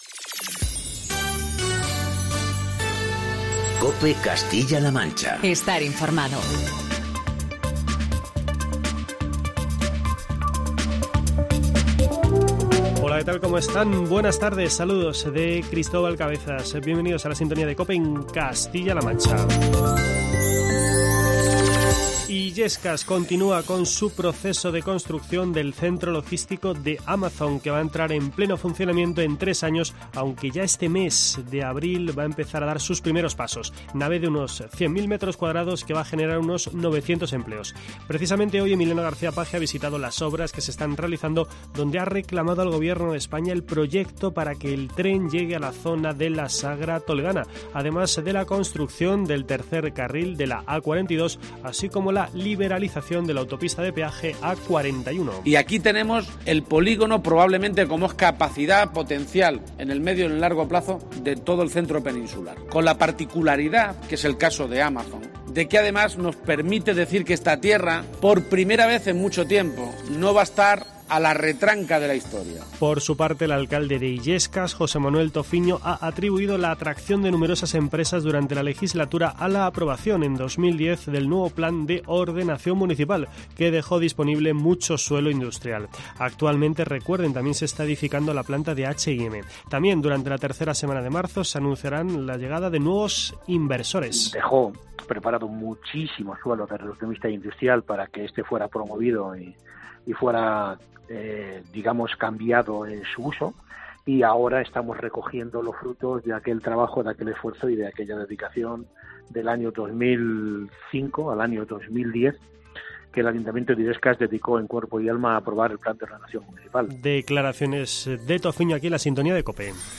Escuchamos las declaraciones del consejero de Sanidad, Jesús Fernández Sanz